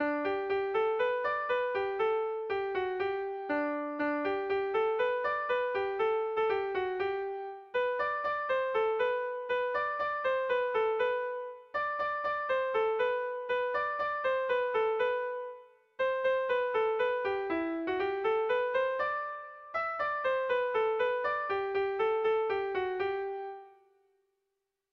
Hamabiko txikia (hg) / Sei puntuko txikia (ip)
AABBDE